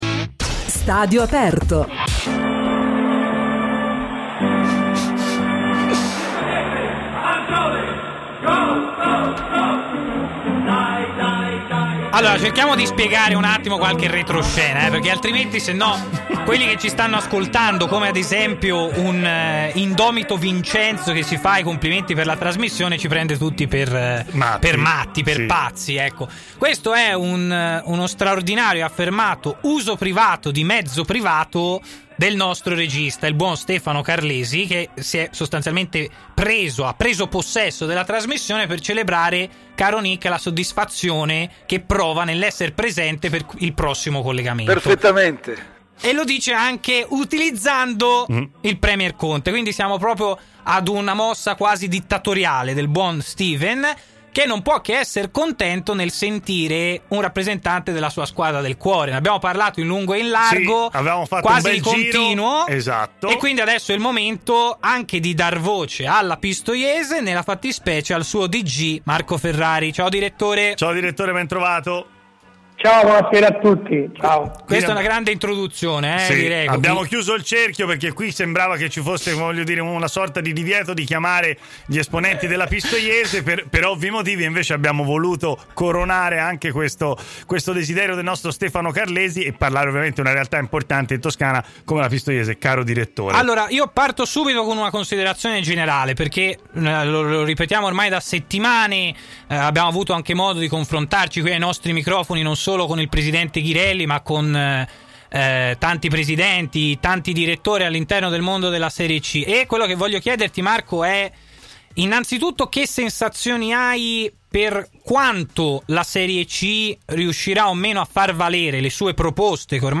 si è collegato in diretta con Stadio Aperto, trasmissione in onda sulle web frequenze di TMW Radio